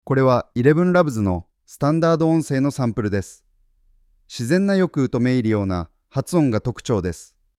音声サンプル：スタンダード音声の例